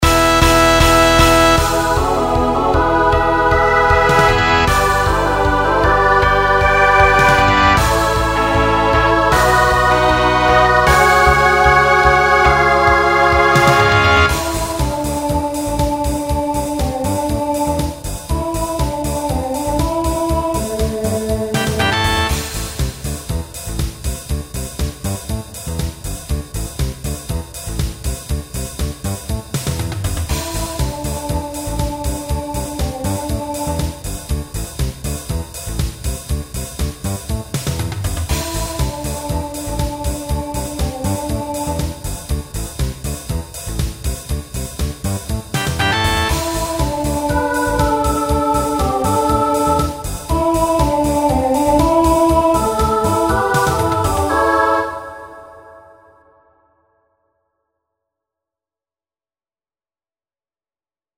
Genre Pop/Dance Instrumental combo
Transition Voicing SSA